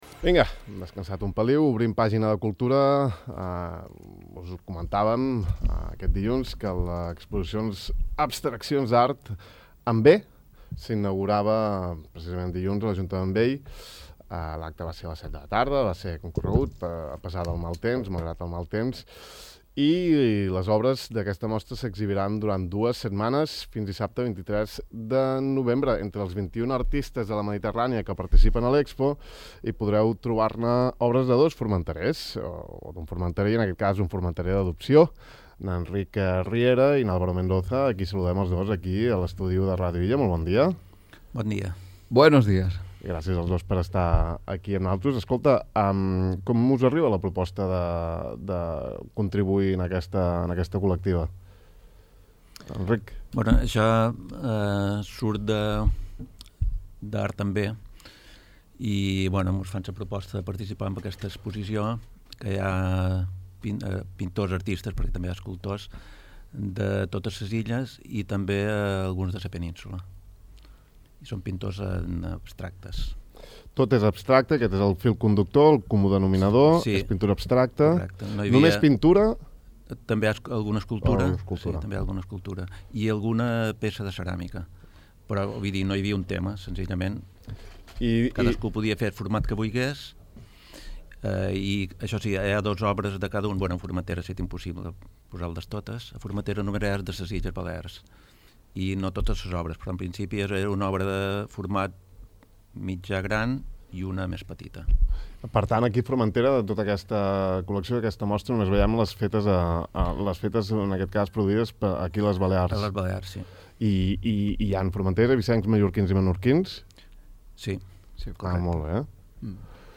Sobre la mostra, les seves aportacions, el procés creatiu i l’abstracció, hem conversat amb ells aquest matí al De far a far: